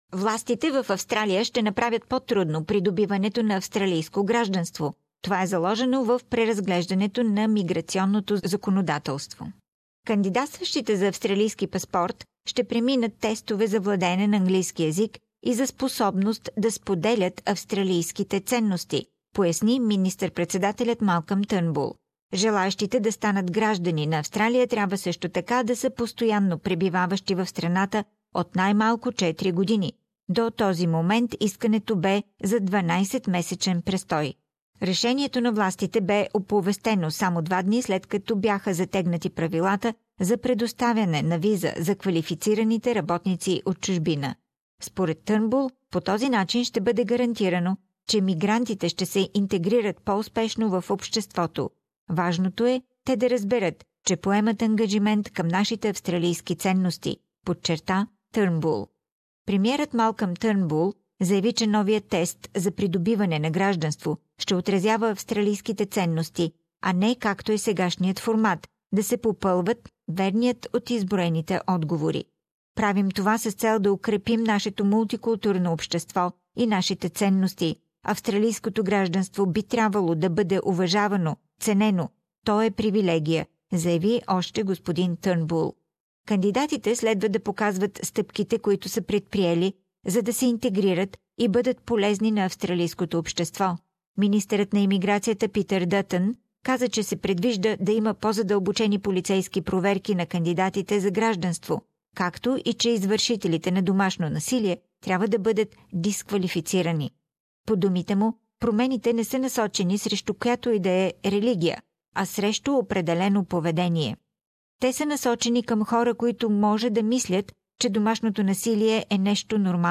Интервю